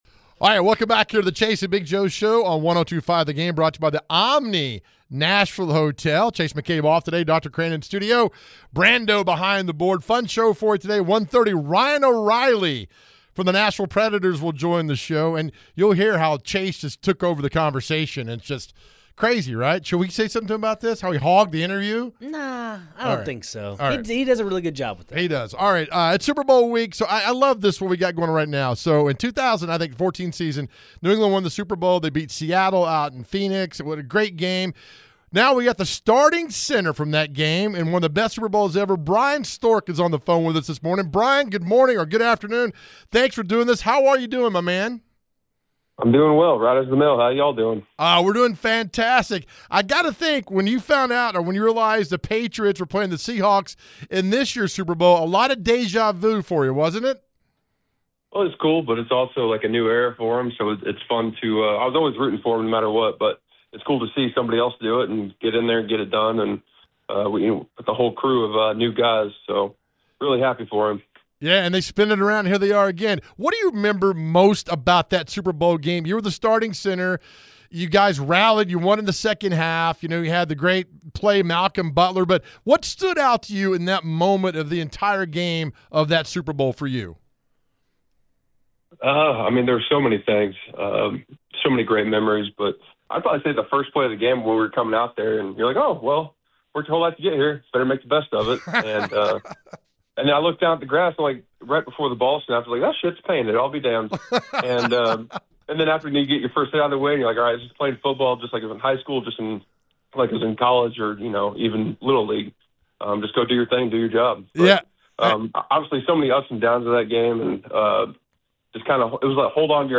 Former NFL center and Super Bowl XLIX champion Bryan Stork joins the show to share his experience with the New England Patriots and their Super Bowl win over the Seahawks in 2015.